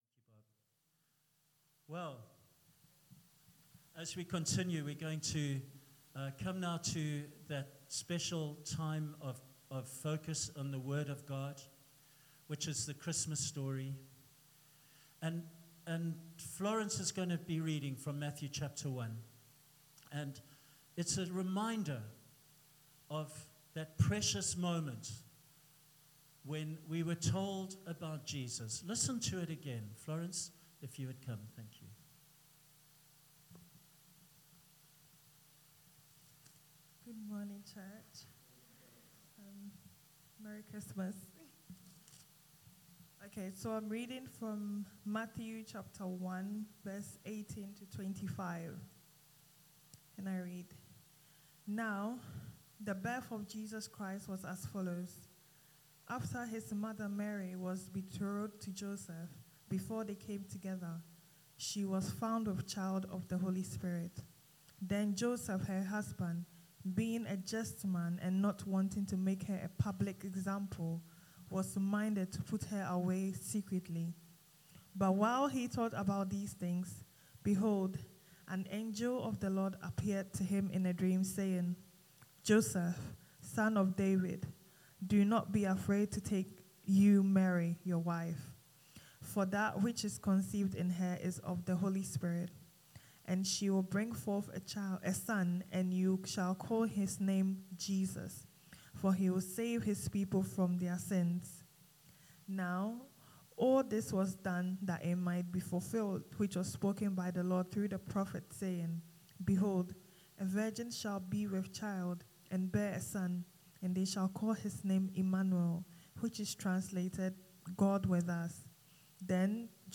A message shared for adults and children alike during our all age Christmas day service. The reading is Matthew chapter 1 verses 18-25. 25th December 2019.